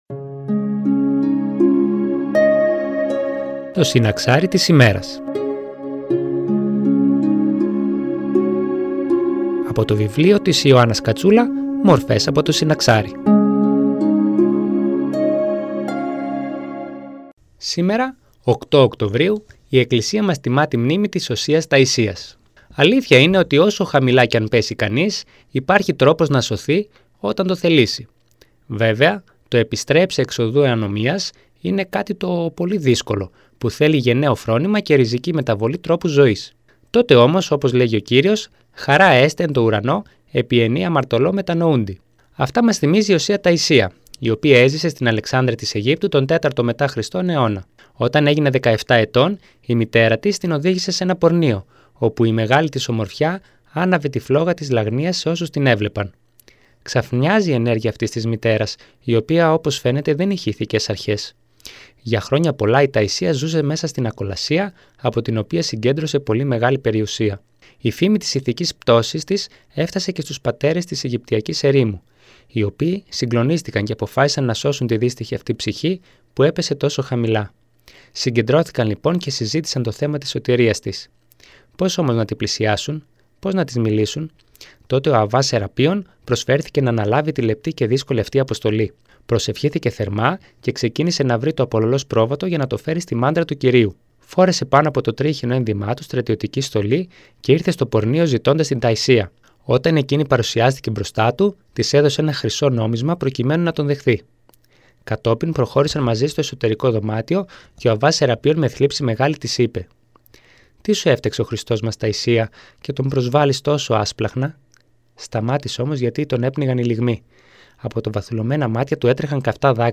Μια ένθετη εκπομπή που μεταδίδεται από Δευτέρα έως Παρασκευή στις 09:25 από την ΕΡΤ Φλώρινας.
Εκκλησιαστική εκπομπή